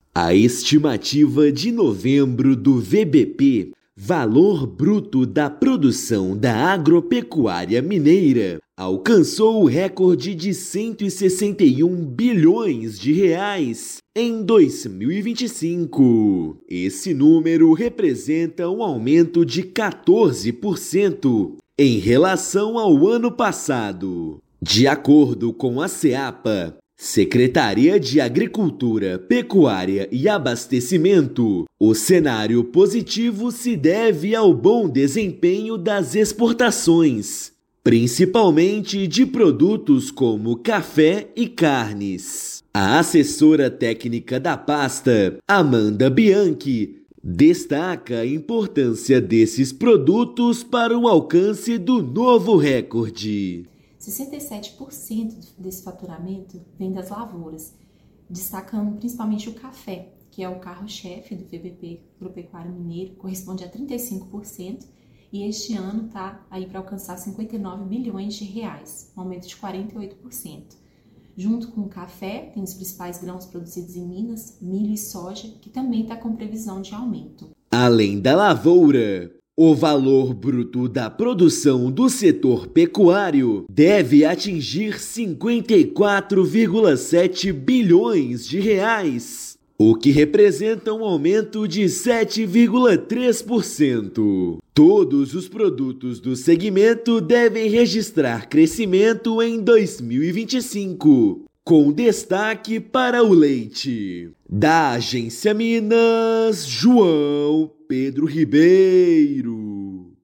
[RÁDIO] Valor Bruto da Produção agropecuária mineira deve alcançar valor recorde de R$ 168,1 bilhões em 2025
A projeção aponta crescimento de 13,8% em relação a 2024, puxado pelo bom desempenho do café. Ouça matéria de rádio.